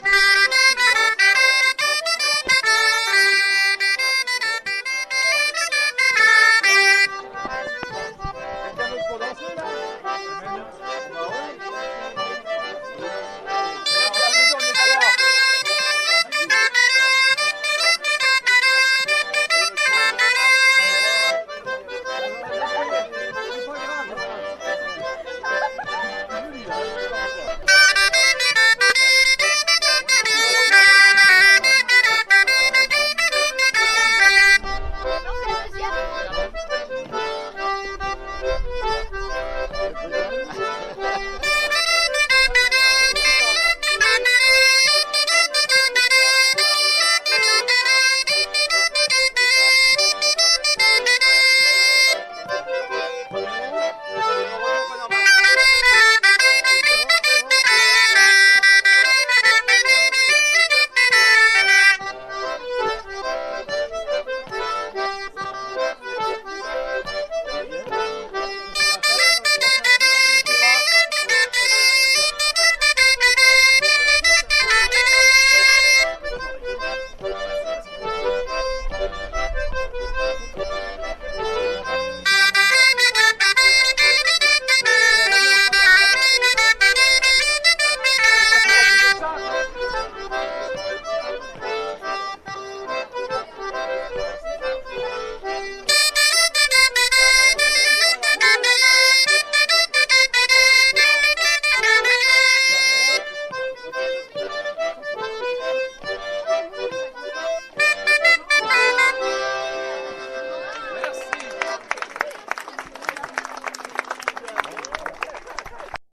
01_laride_accordeon_bombarde.mp3